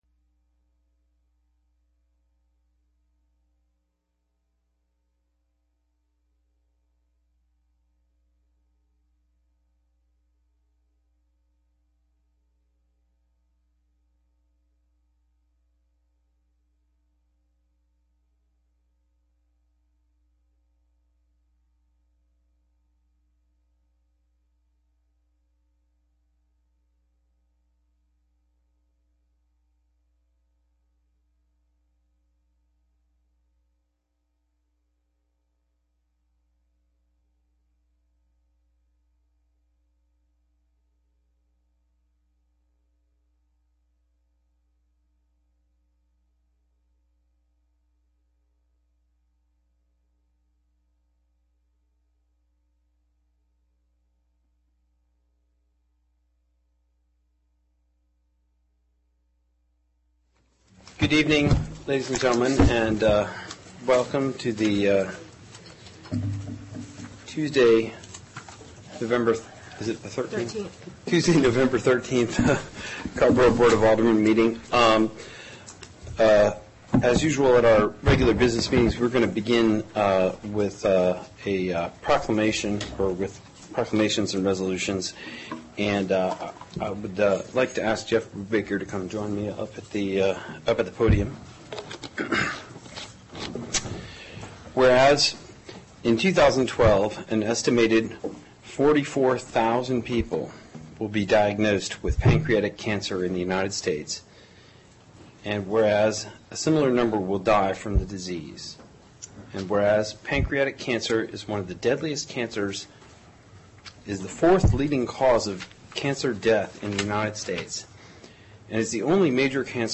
AGENDA CARRBORO BOARD OF ALDERMEN Regular Meeting Tuesday, November 13, 2012 7:30 P.M., TOWN HALL BOARD ROOM
*Please note that speakers from the floor are requested to limit their comments to three minutes.